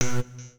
Universal UI SFX / Clicks
UIClick_Alien Interface 02.wav